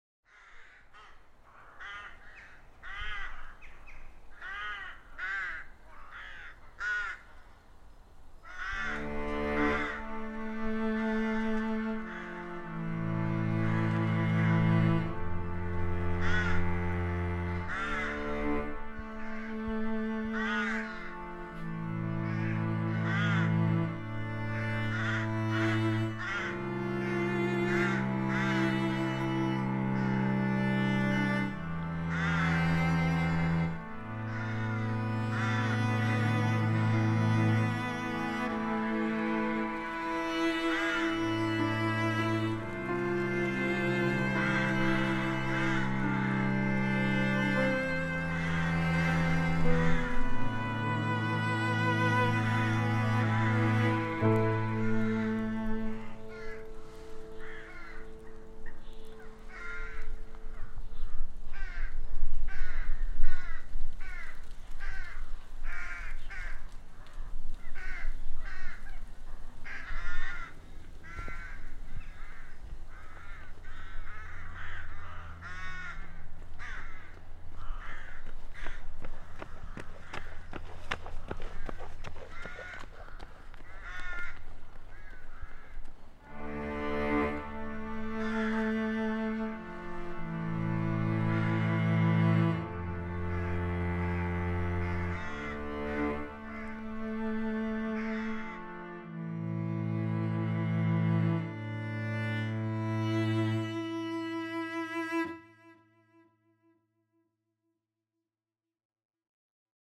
Crows at Blenheim Palace reimagined